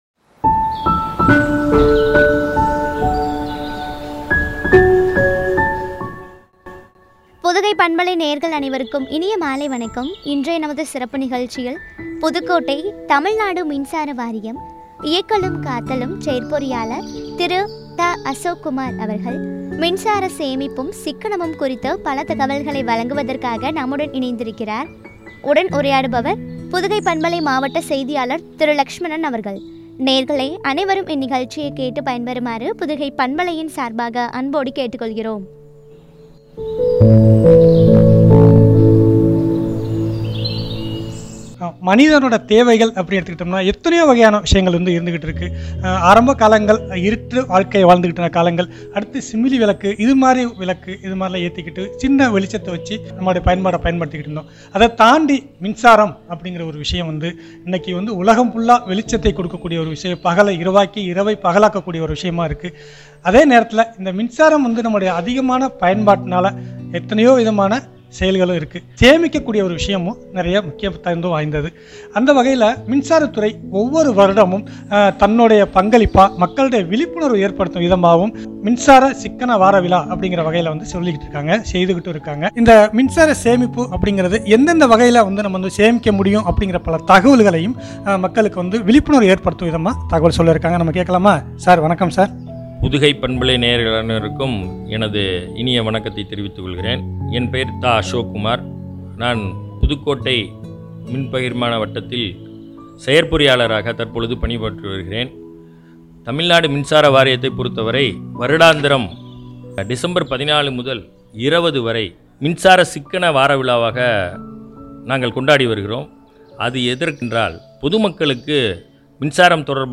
சேமிப்பும் பற்றிய உரையாடல்.